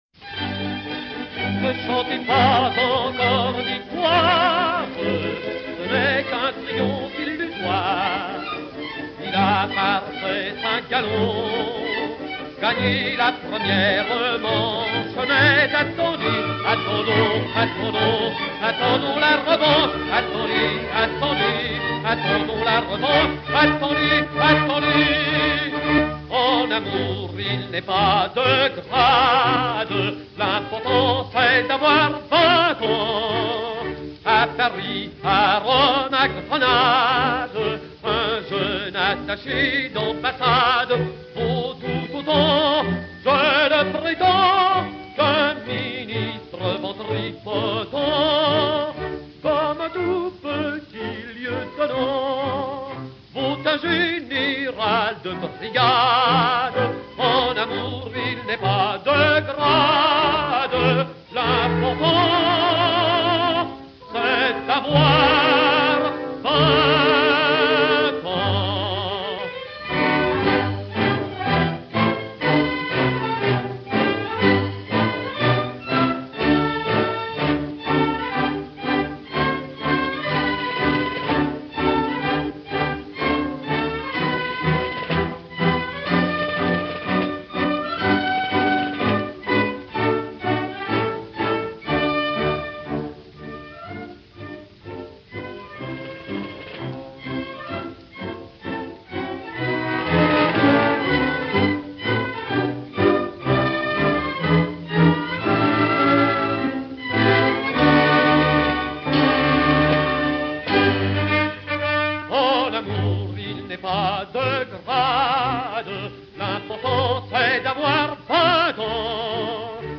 et Orchestre dir.